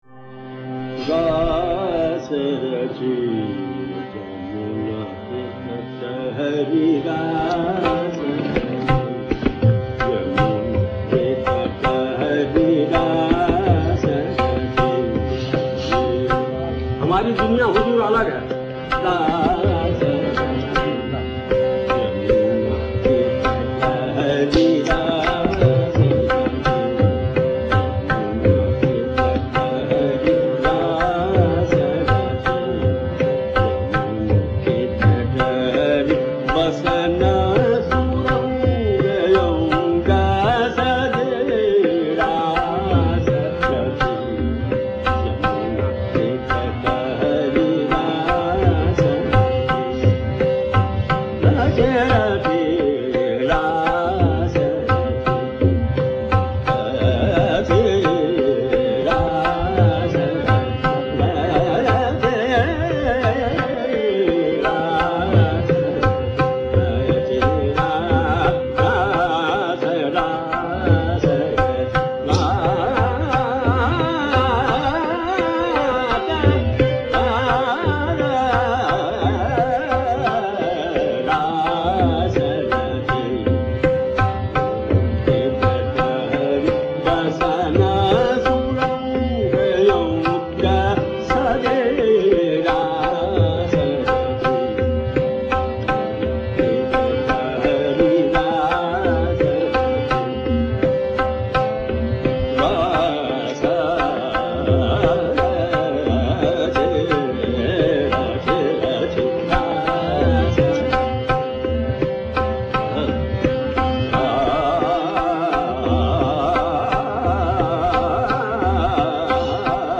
Hem Nat is a hybrid formed by a judicious blend of Ragas Hem and Nat.
The words are so chosen as to create a mild staccato effect to accord to Hem’s catch phrase P’ D’ P’ S (Tabla
Harmonium